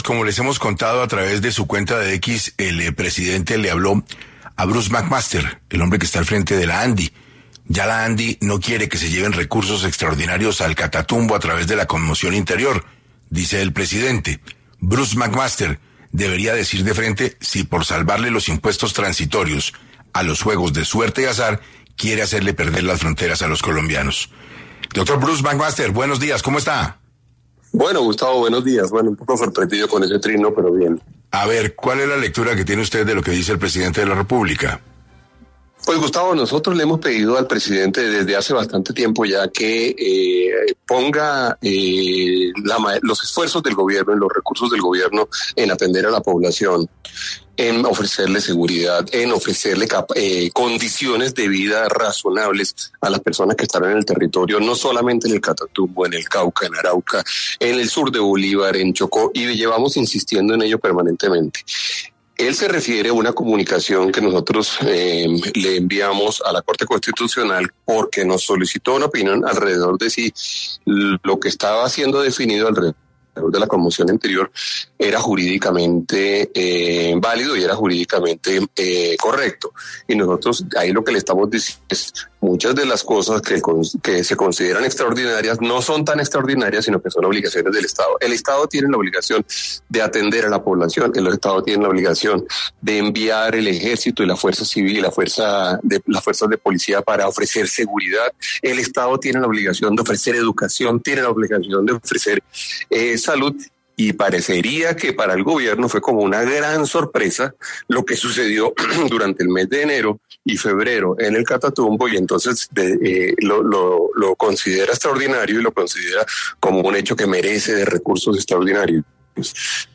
Bruce Mac Máster, presidente de la ANDI, estuvo en 6AM para discutir sobre las reacciones del presidente Petro tras la petición de la Asociación de tumbar el Estado Conmoción Interior en el Catatumbo.
Ante esta coyuntura, Bruce Mac Master, presidente de la ANDI, pasó por los micrófonos de 6AM para expresar su posición frente a las declaraciones del presidente.